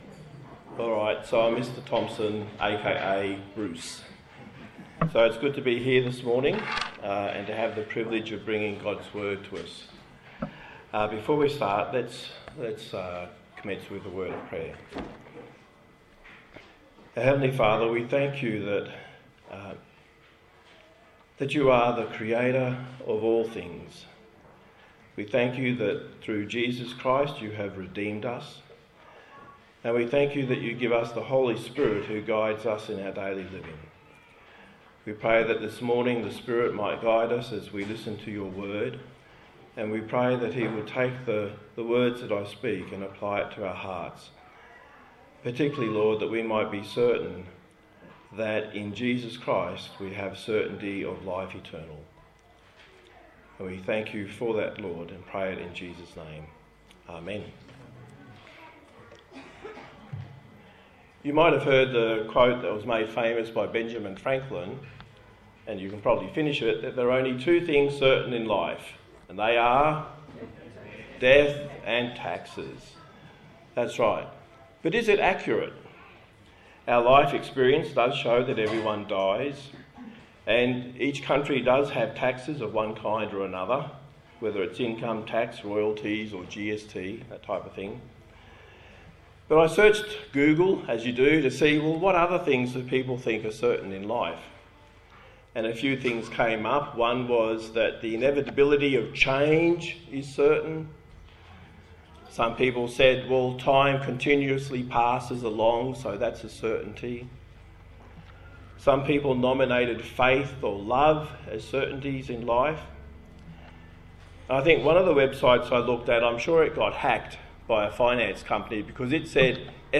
Philippians Passage: Philippians 1:1-11 Service Type: Sunday Morning